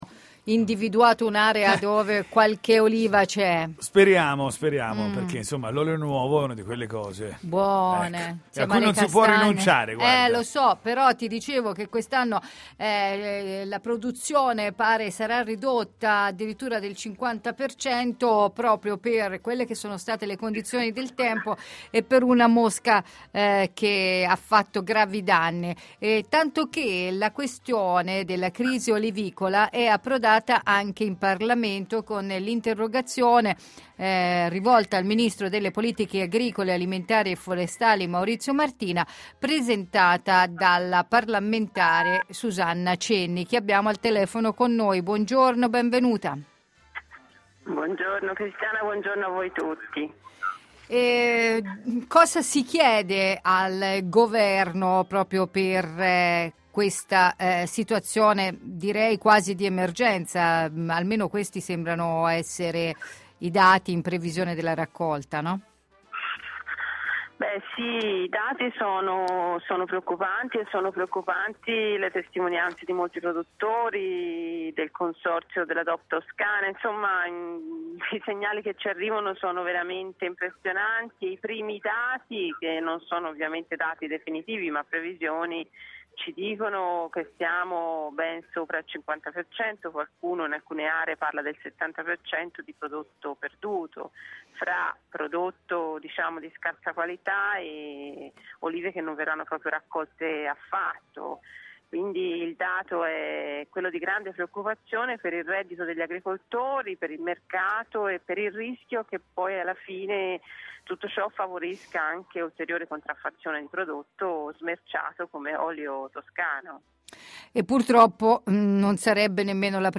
Susanna Cenni deputato Pd parla di filiera dell'olio toscano - Antenna Radio Esse